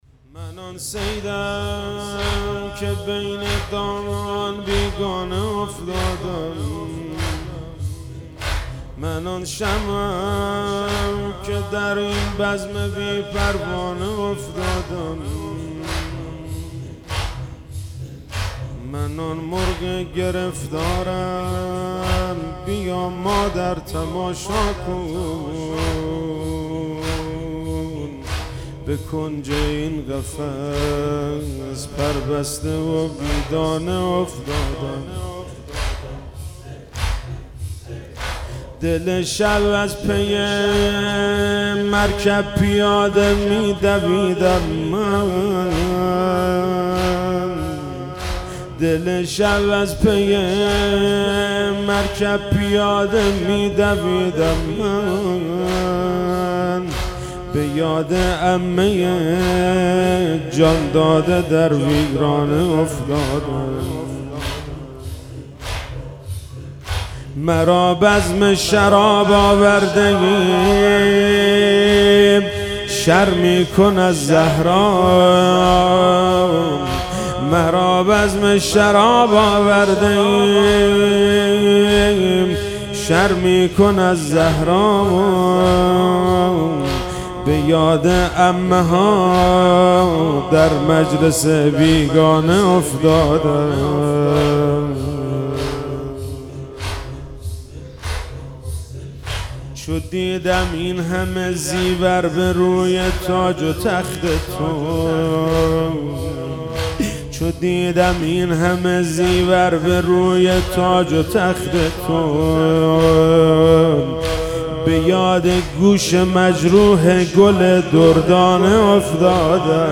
شب شهادت امام هادی (ع) 98 - زمینه - من آن صیدم
شهادت امام هادی علیه السلام